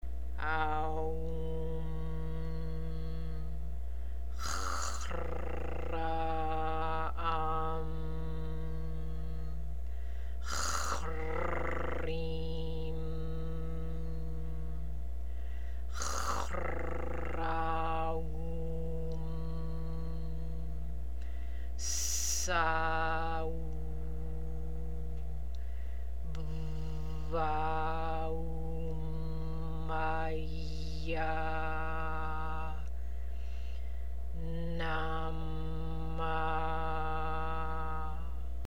МАНТРА ЗА МАРС:
Произношение:
AАА-УУУ-MMM ٠ ХХХ-РР-AА-AА-MM ٠ ХХХ-РР-ИИ-MM ٠ ХХХ-РР-AА-УУ-MM ٠ ССА-УУУ ٠ ББ-ААА-УУУ-ММ-АА-ИЯА ٠ НАА-MAА
"Х" се вибрира в задната част на гърлото (силно гърлено).
5 - Mars Square Mantra.mp3